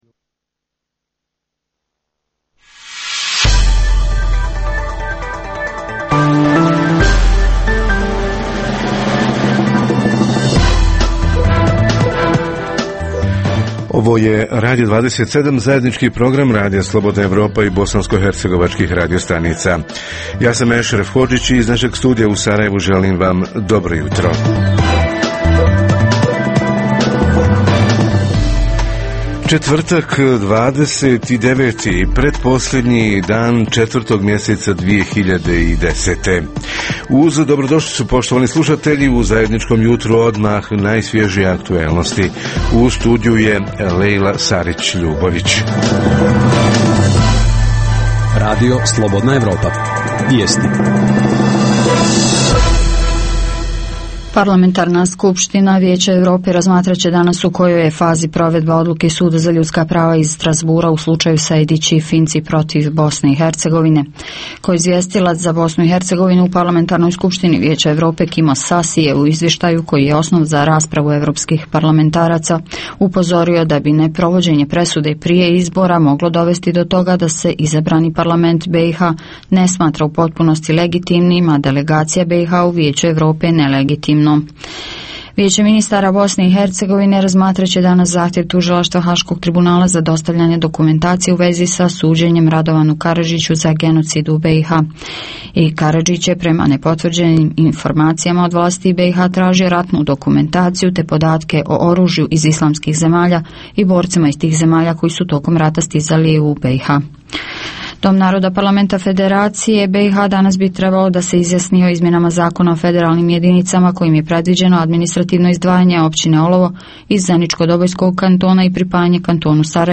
Mladi i ovisnost o alkoholu – šta su uzroci, a šta posljedice i kako do izliječenja? Reporteri iz cijele BiH javljaju o najaktuelnijim događajima u njihovim sredinama.
Redovni sadržaji jutarnjeg programa za BiH su i vijesti i muzika.